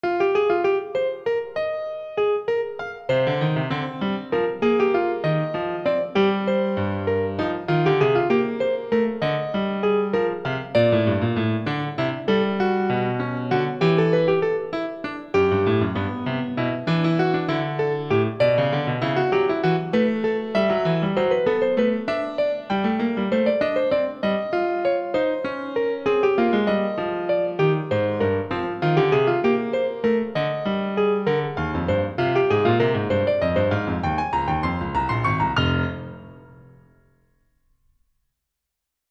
A collection of short solo piano pieces for the intermediate to advanced high school piano student or college piano major.